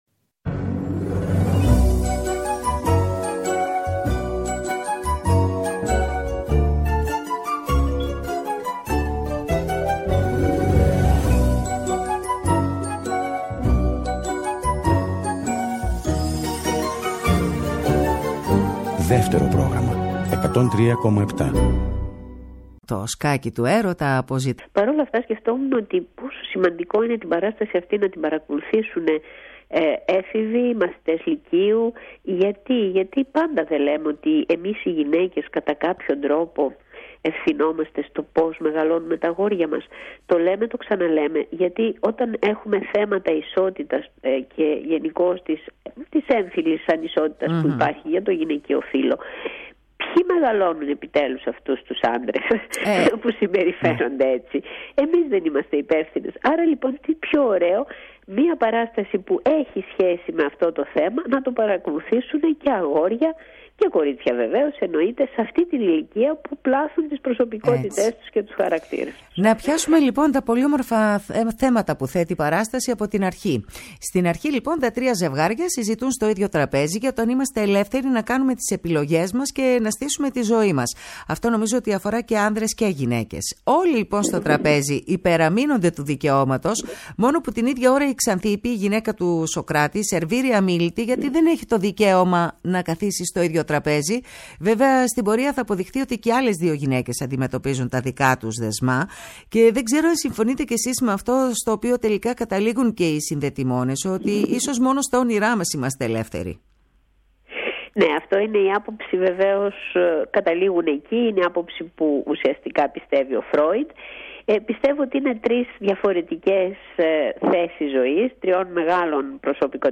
«Έχει η ζωή γυρίσματα» Μία δίωρη ραδιοφωνική περιπλάνηση, τα πρωινά του Σαββατοκύριακου.